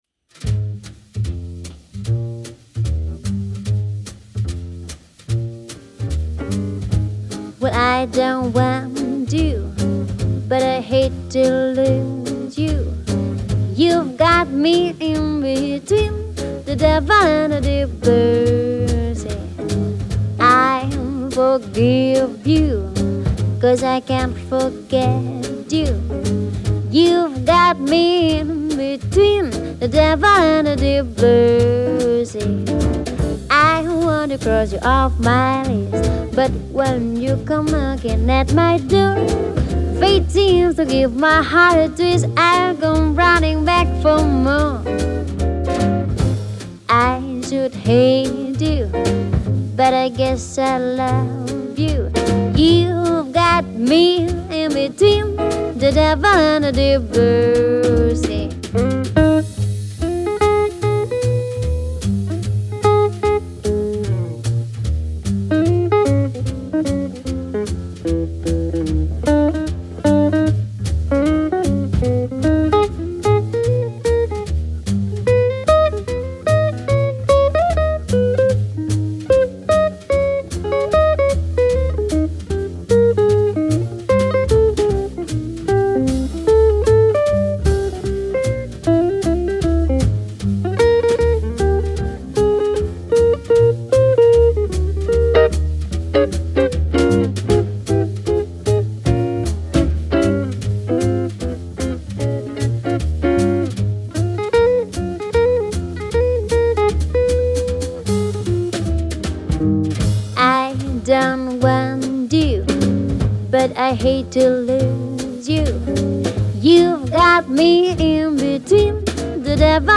trompette, saxophone, chant
piano
saxophone et contrebasse
batterie